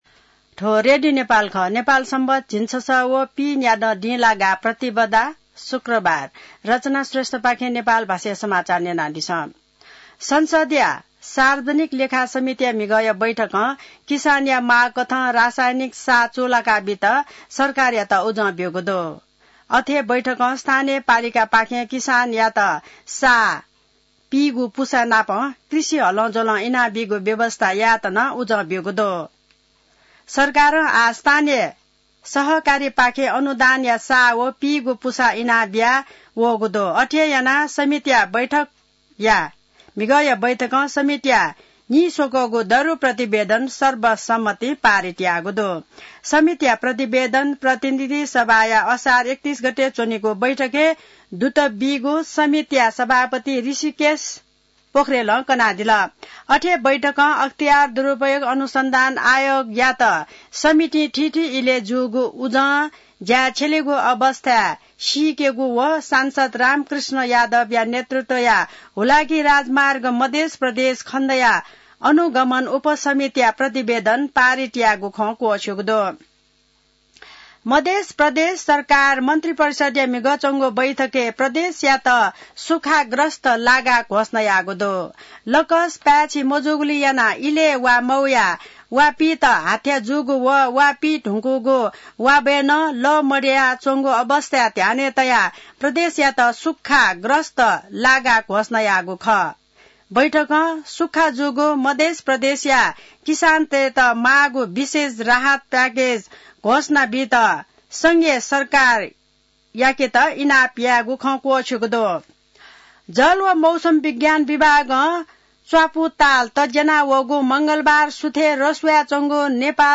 नेपाल भाषामा समाचार : २७ असार , २०८२